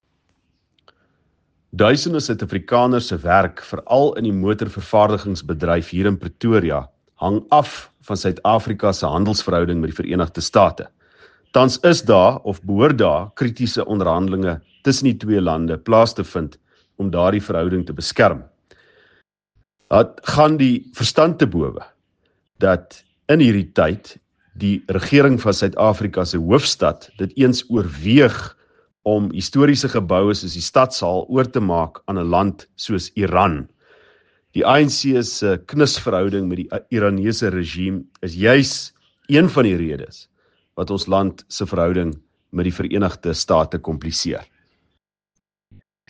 Issued by Ald Cilliers Brink – DA Tshwane Caucus Leader
Note to Editors: Please find English and Afrikaans soundbites by Ald Cilliers Brink here and